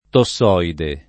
tossoide [ to SS0 ide ]